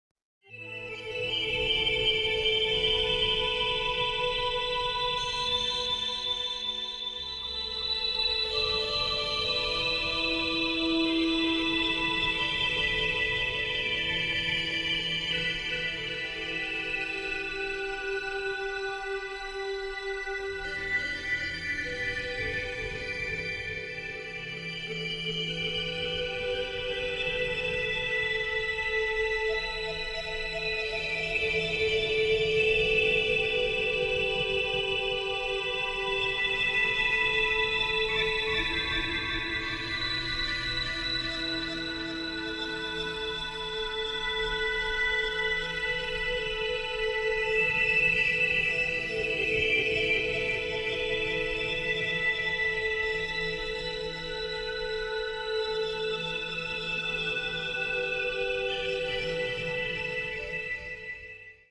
All such variations created different unique music.